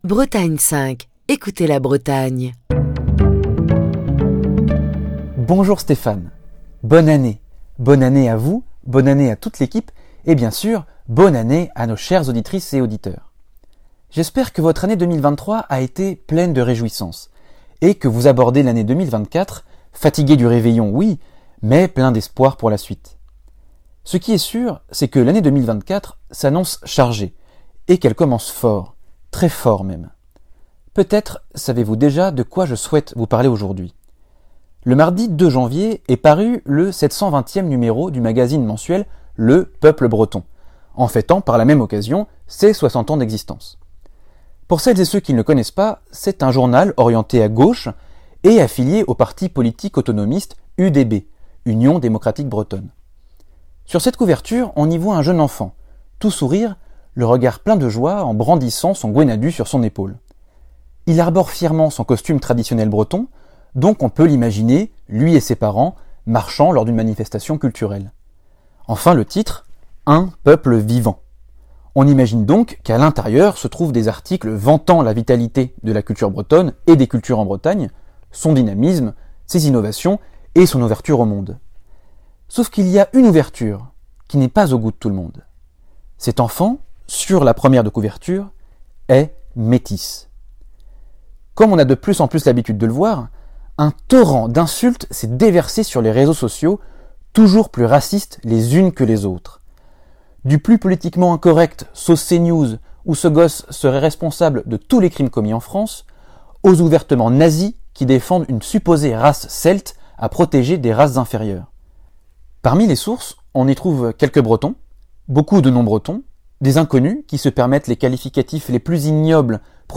Chronique du 8 janvier 2024.